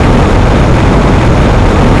sci-fi_vehicle_spaceship_jet_engine_loop3.wav